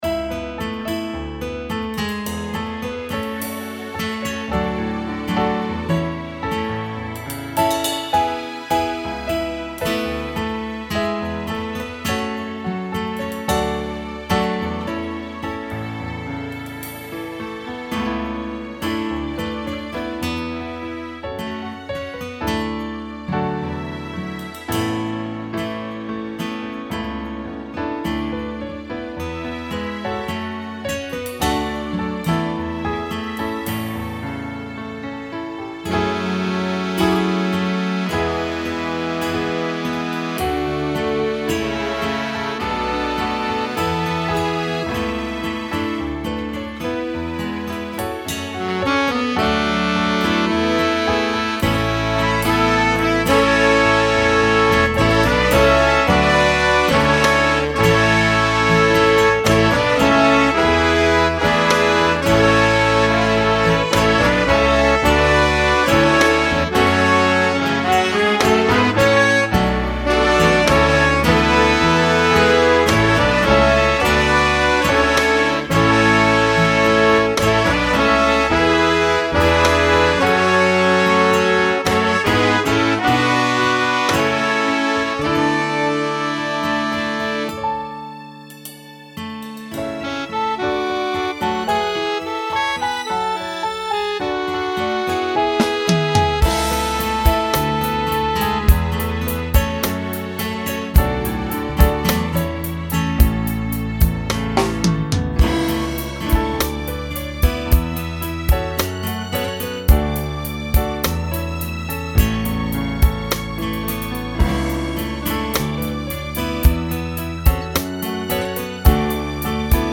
Voicing SATB Instrumental combo Genre Country
Function Ballad